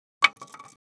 Index of /traerlab/AnalogousNonSpeech/assets/stimuli_demos/jittered_impacts/small_styrofoam_longthin_plank